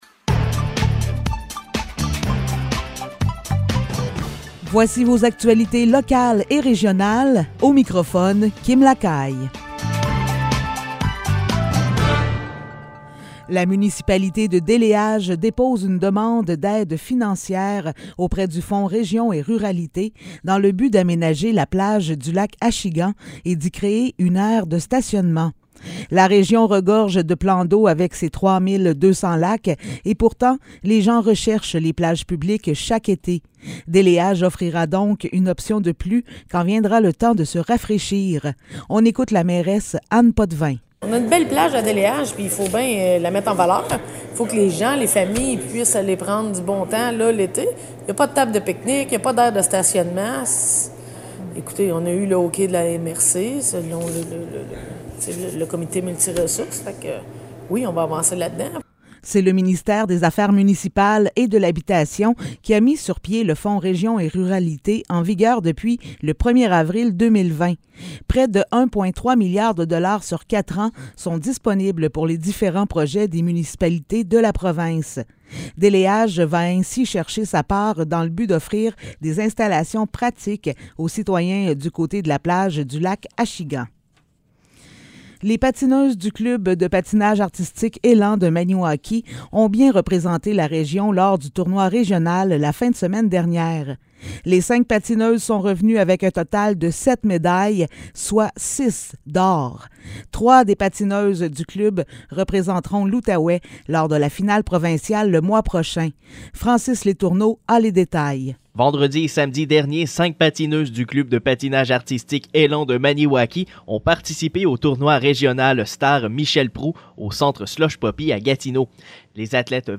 Nouvelles locales - 8 mars 2022 - 15 h